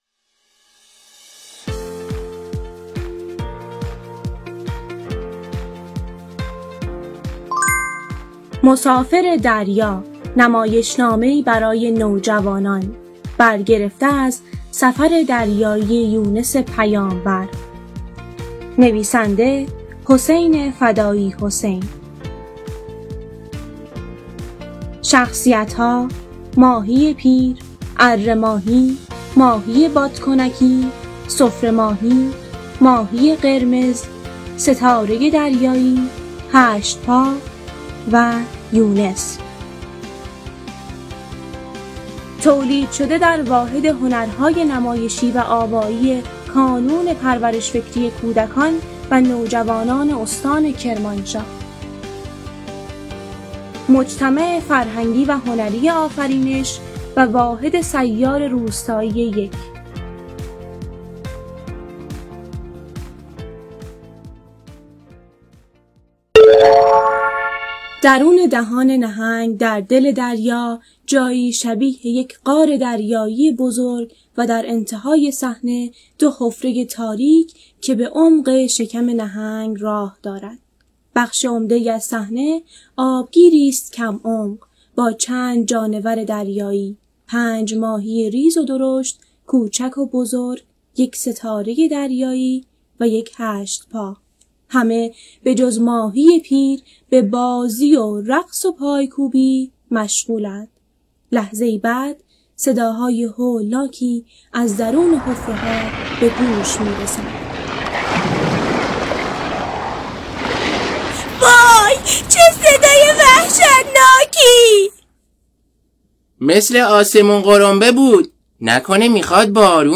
نمایشنامه‌ «مسافر دریا» روایت سفر دریایی یونس پیامبر، نوشته‌ی حسین فدایی‌حسین به صورت فایل صوتی نمایشنامه‌خوانی از سوی واحد هنرهای نمایشی و آوایی کانون کرمانشاه تولید و منتشر گردید.